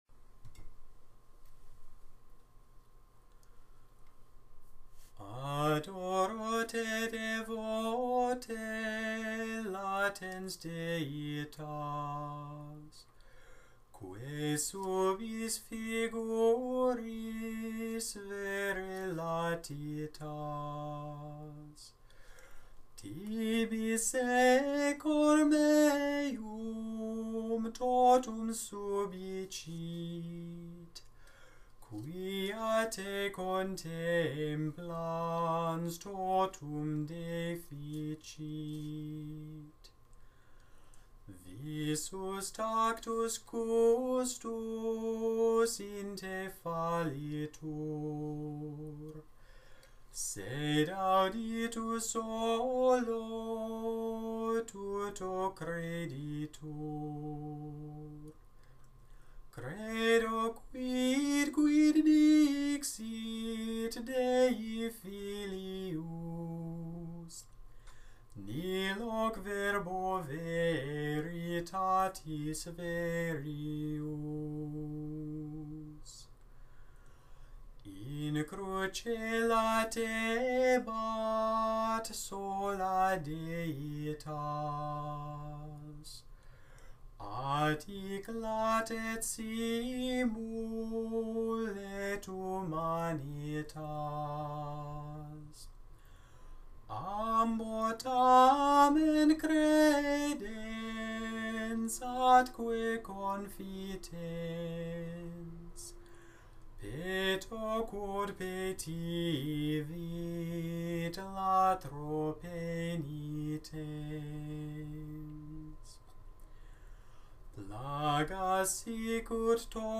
Gregorian chant audios, Adoro Te Devote
Adoro Te is the quintessential communion hymn, with lyrics written by St. Thomas Aquinas.